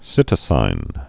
(sĭtə-sīn)